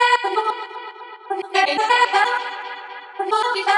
• chopped vocals ping pong house delayed (4) - Em - 128.wav
chopped_vocals_ping_pong_house_delayed_(4)_-_Em_-_128_Hk6.wav